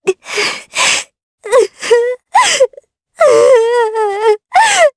Leo-Vox_Sad_jp.wav